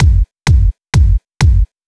VTDS2 Song Kit 04 Female Keep Believing Kick.wav